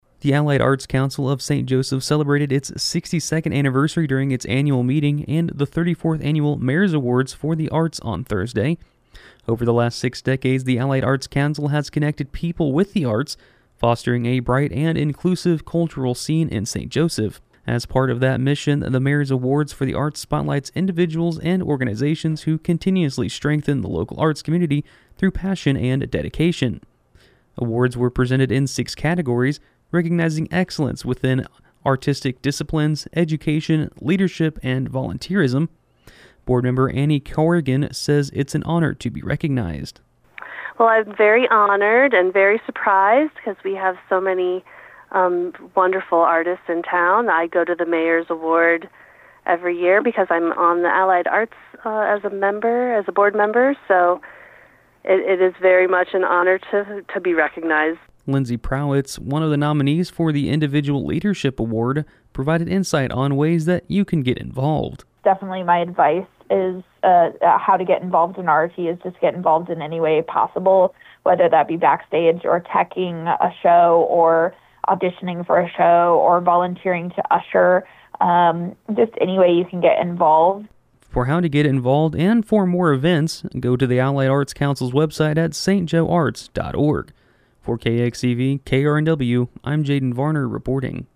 News Brief